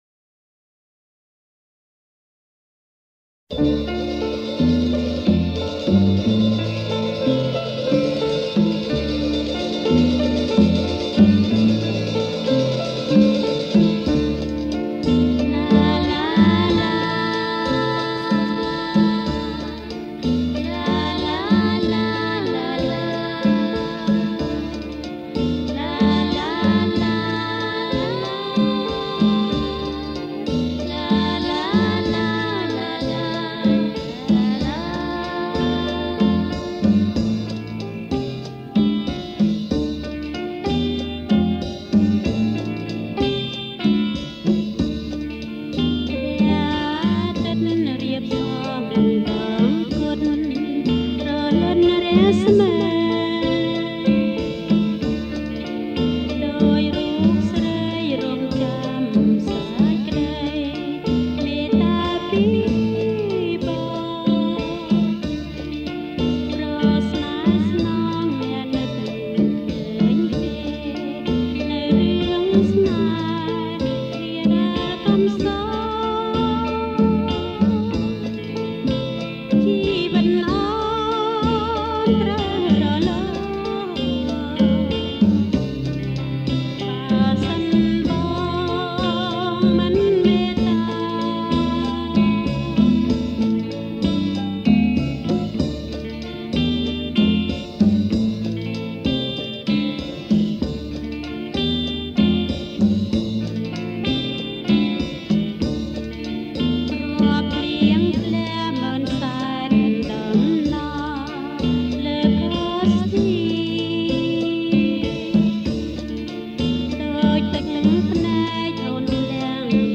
• ប្រគំជាចង្វាក់ Slow Jerk